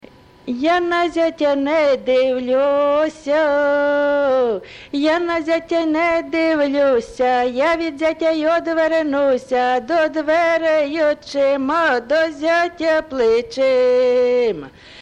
ЖанрВесільні
Місце записус. Яблунівка, Костянтинівський (Краматорський) район, Донецька обл., Україна, Слобожанщина